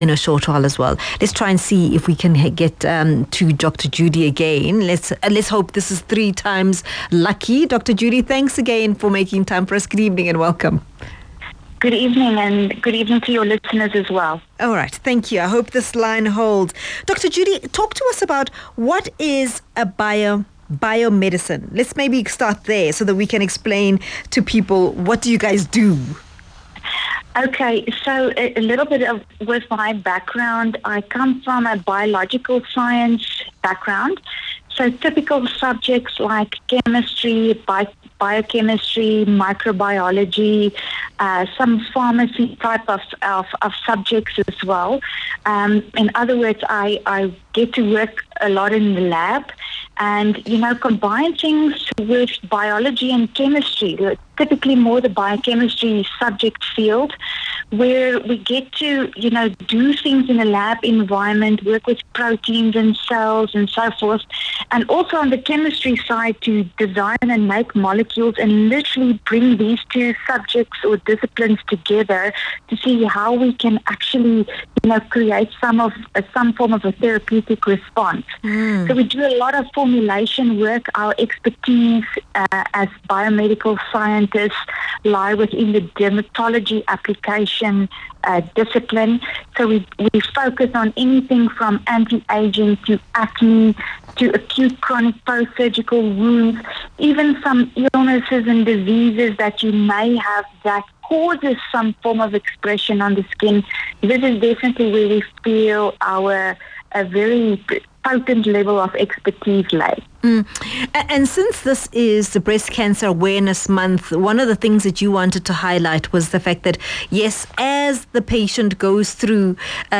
talking to Kaya FM about how looking after our skin while someone...